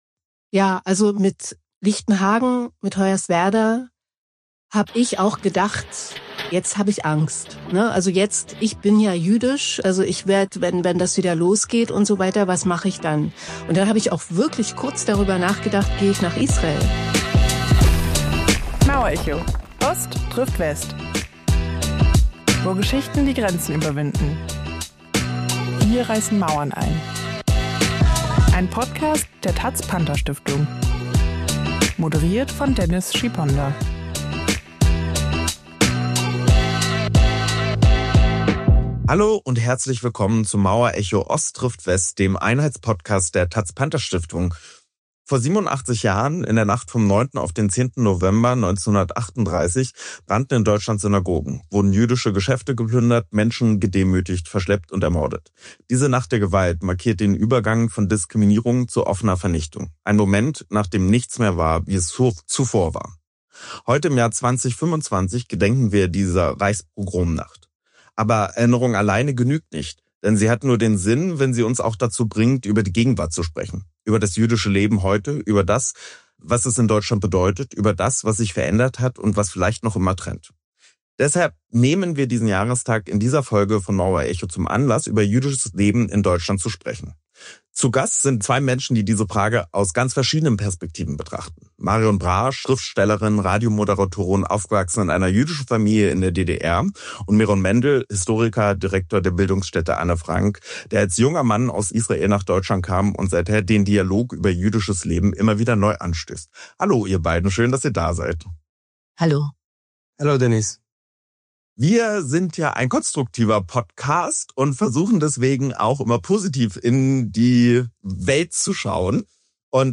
Zu Gast sind diesmal die Schriftstellerin und Radioautorin Marion Brasch sowie der Historiker und Direktor der Bildungsstätte Anne Frank Meron Mendel.